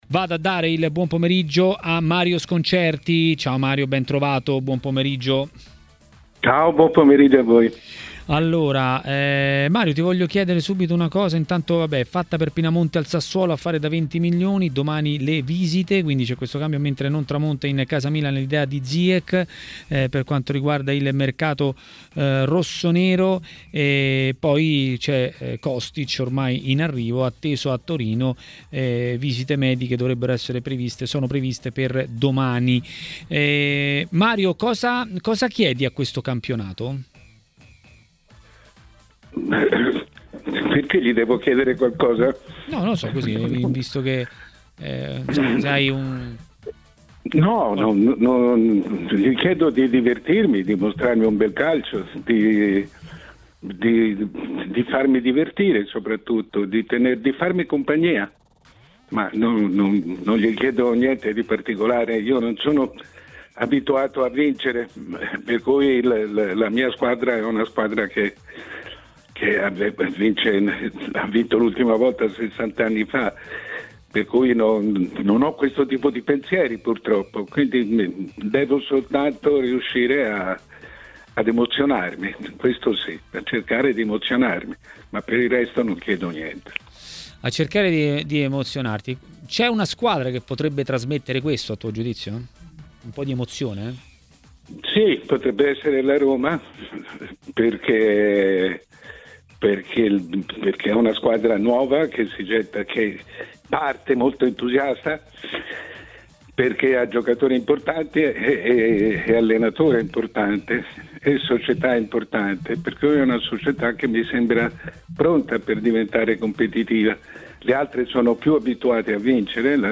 Per commentare le notizie di giornata a Maracanà, trasmissione di TMW Radio, è intervenuto il direttore Mario Sconcerti.